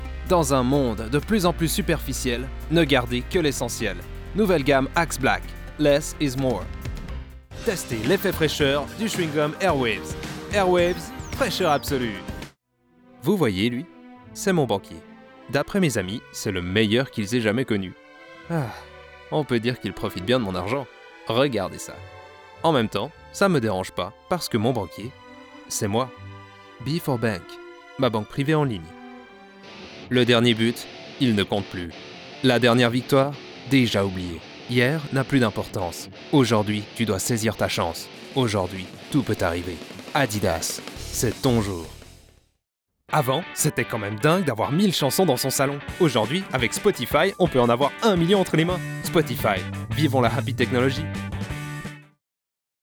From my home studio I will deliver a professional, clean voice over track.
Sprechprobe: Werbung (Muttersprache):
Commercials_French France_With_Music.mp3